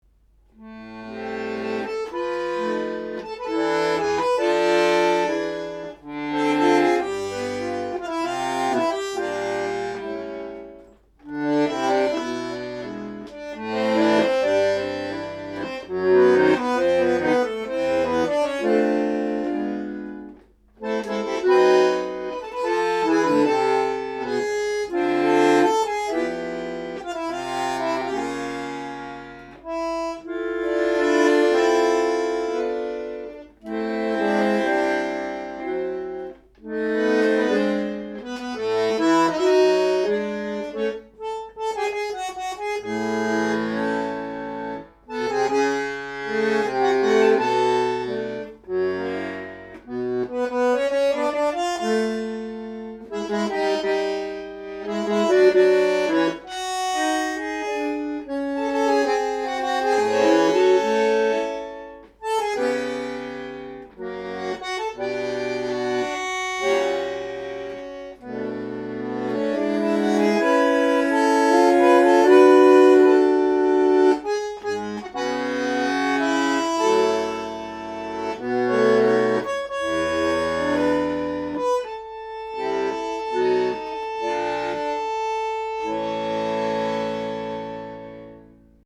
bandoneonBandoneon